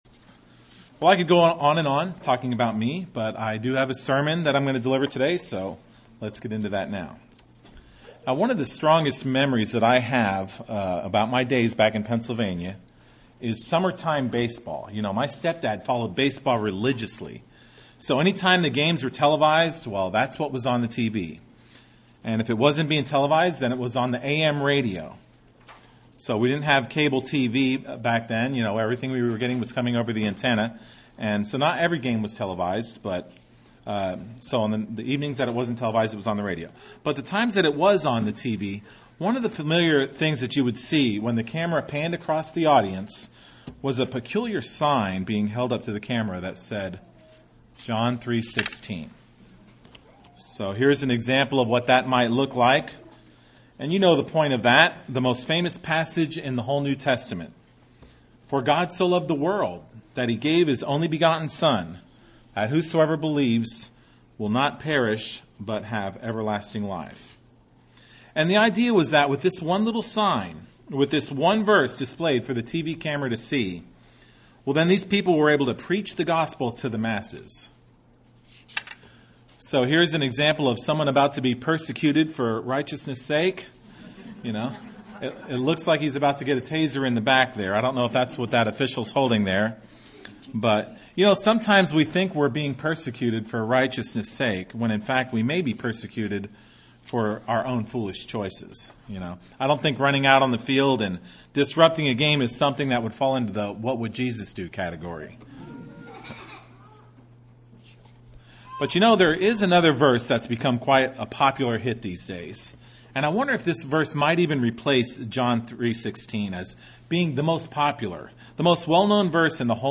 This is a sermon that goes over the role of a Christian in passing judgement on others.